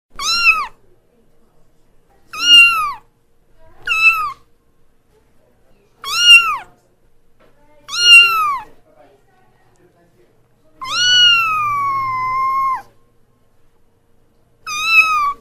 Звук - Мяуканье котенка
Отличного качества, без посторонних шумов.